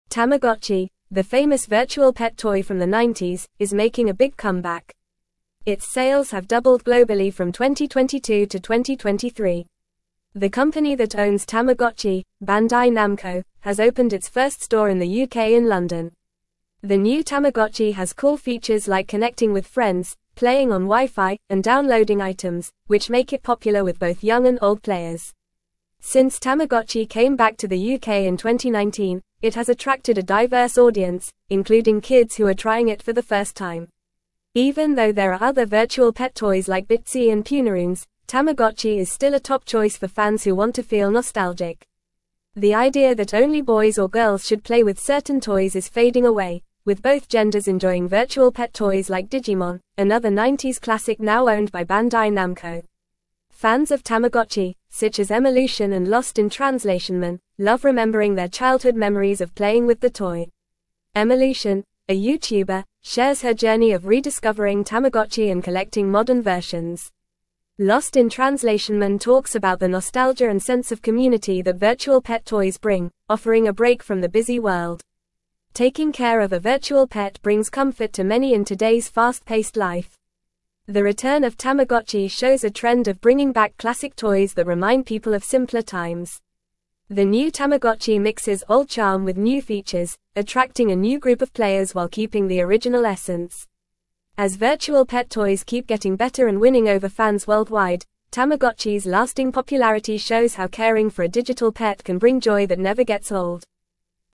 Fast
English-Newsroom-Upper-Intermediate-FAST-Reading-Tamagotchi-Makes-Strong-Comeback-with-Modern-Features-and-Nostalgia.mp3